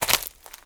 STEPS Leaves, Walk 16.wav